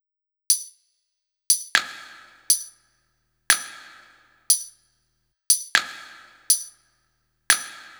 Bp Clik Loop.wav